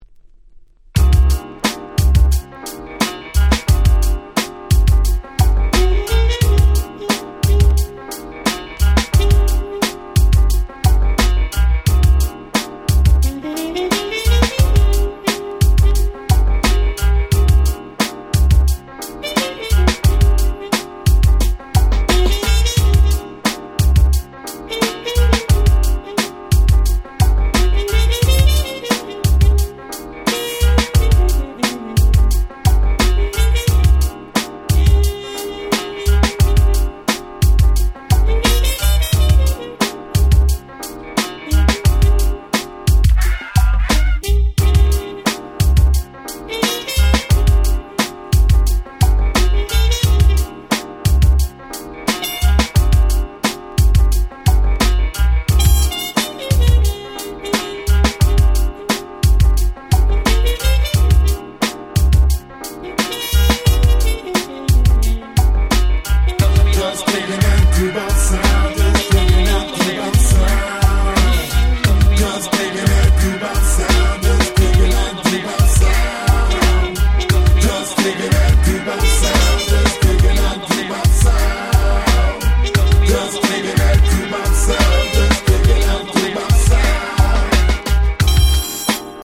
92' Super Nice Jazzy Hip Hop !!
ジャズ ジャジー 90's Boom Bap ブーンバップ